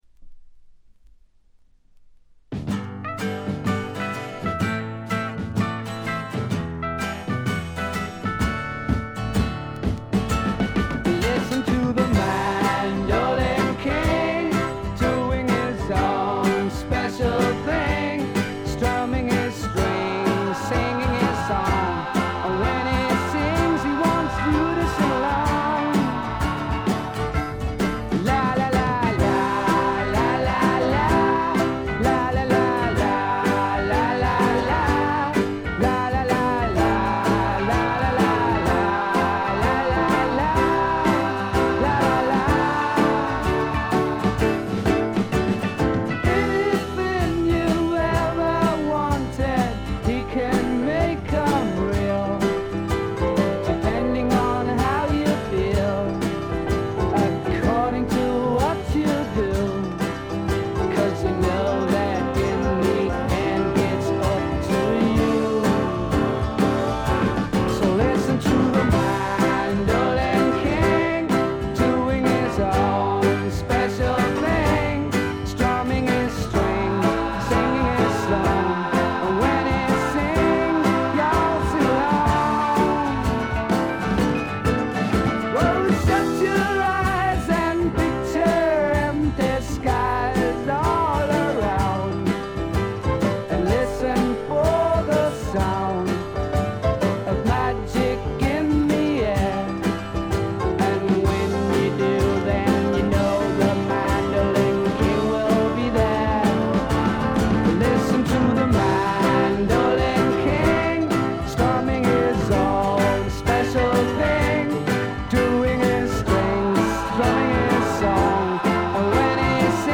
ほとんどノイズ感無し。
これぞ英国流フォークロックとも言うべき名作です。
試聴曲は現品からの取り込み音源です。